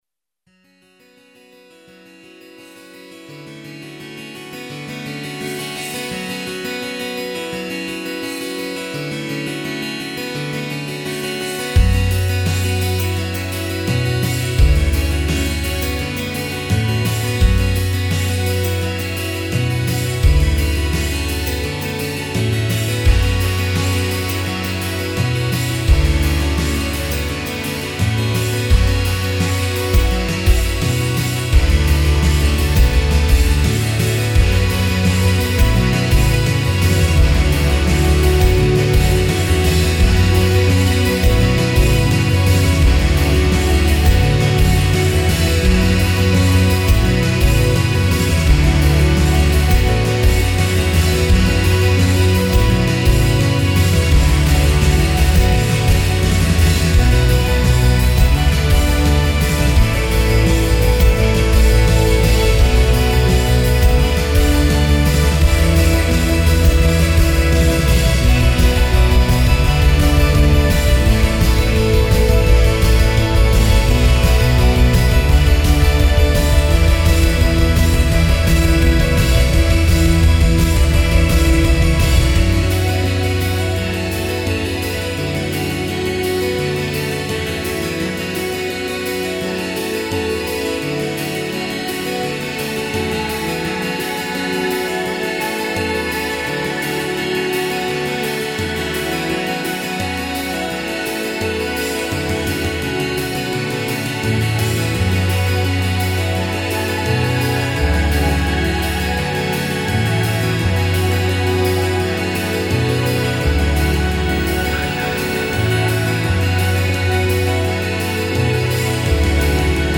Ballad Rock